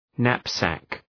{‘næp,sæk}